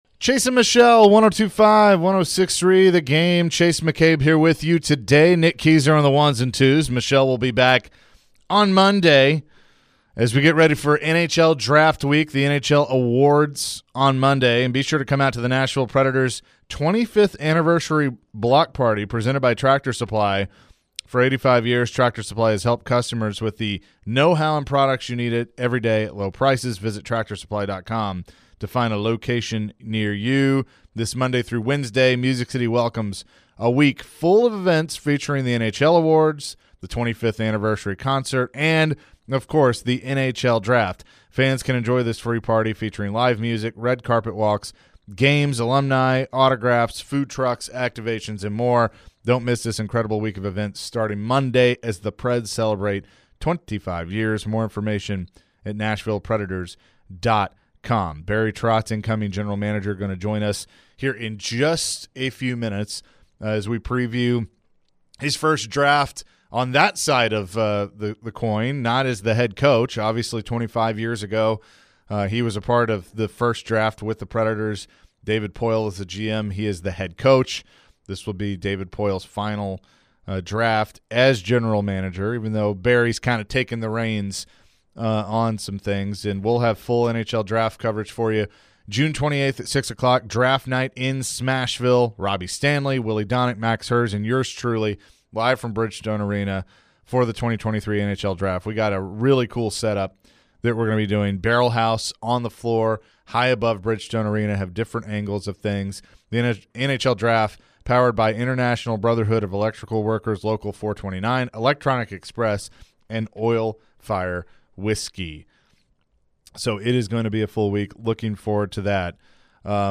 The NHL Draft is next week here in Nashville and there is plenty of stories to cover. Greg Wyshynski joined the show to discuss the Draft, and General Manager Barry Trotz joined as well to discuss what he could possibly do during his first draft as GM.